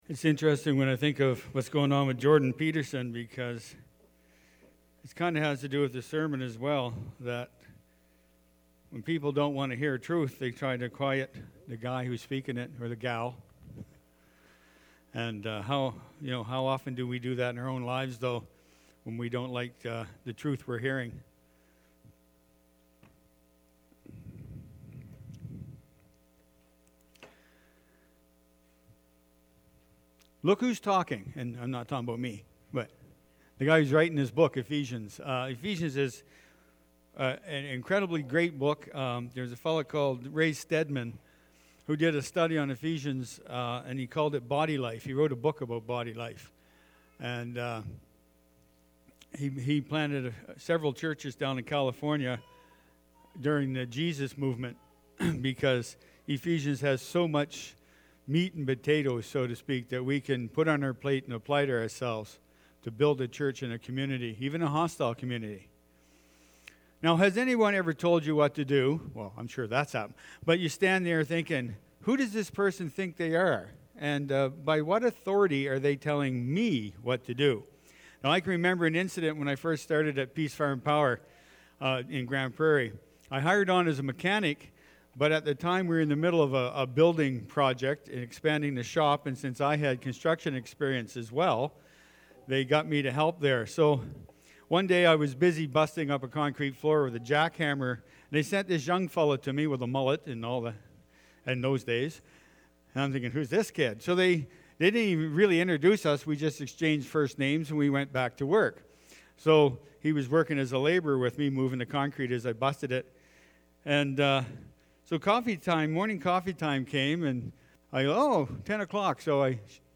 Jan-8-2023-sermon-audio.mp3